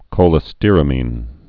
(kōlĭ-stîrə-mēn, kō-lĕstə-rămēn)